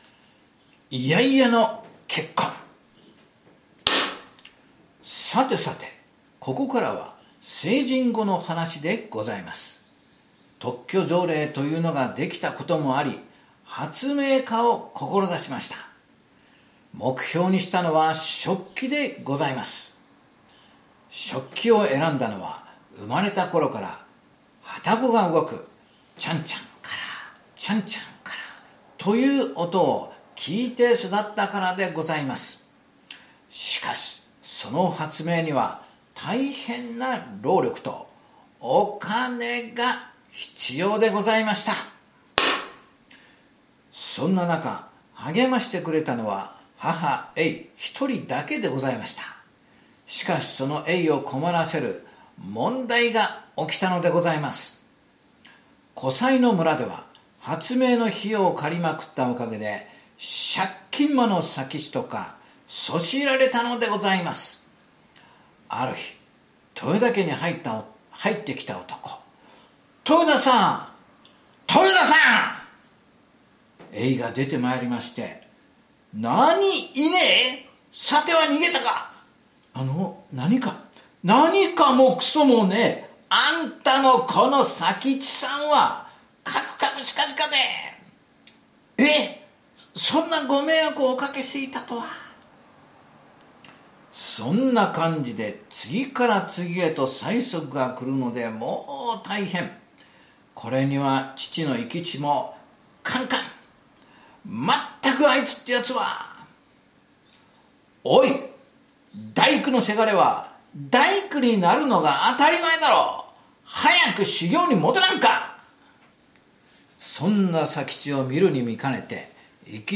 講談 やらまいか豊田佐吉傳 | 「小説やらまいか 豊田佐吉傳」令和元年９月発売